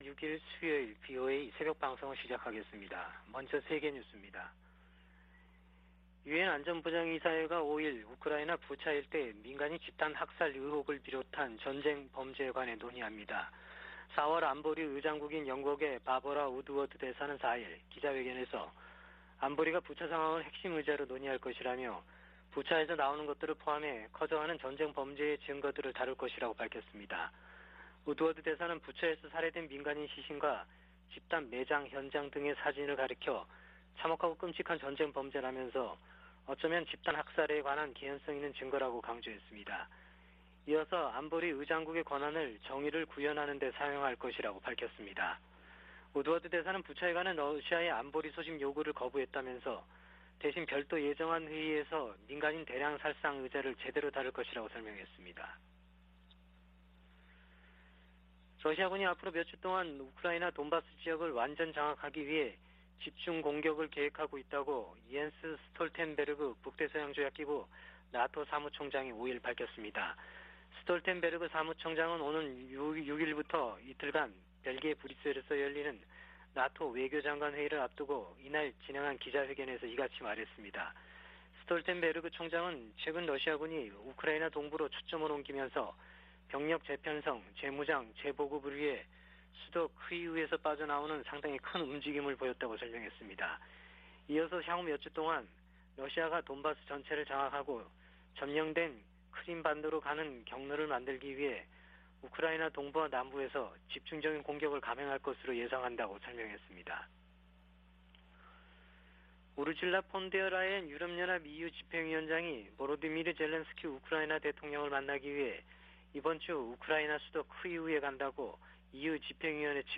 VOA 한국어 '출발 뉴스 쇼', 2022년 4월 6일 방송입니다. 미 국무부는 한국의 윤석열 차기 정부가 전략동맹 강화를 언급한 것과 관련해 “한국은 중요한 조약 동맹”이라고 말했습니다. 미·한 북핵대표가 북한의 최근 ICBM 발사에 대한 새 유엔 안보리 결의안 추진 의사를 밝혔습니다.